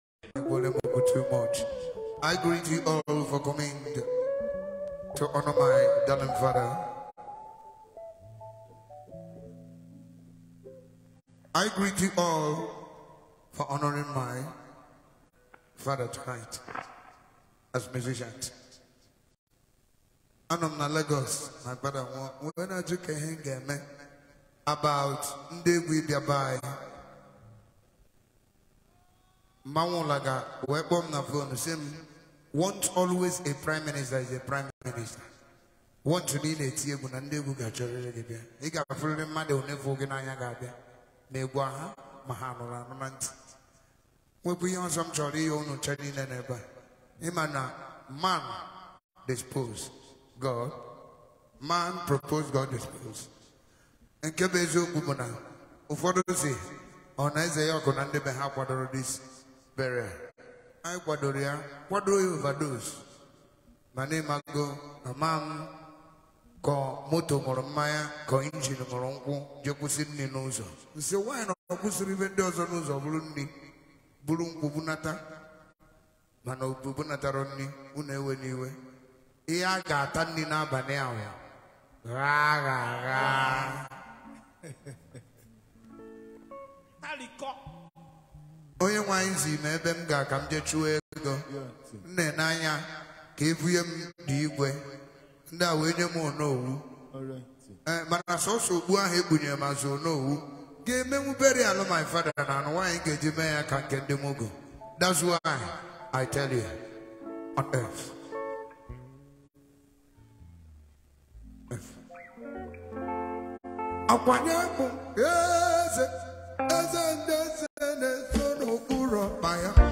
September 9, 2024 admin Highlife Music, Music 0